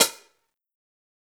Closed Hats
HIHAT_SAVE_ME.wav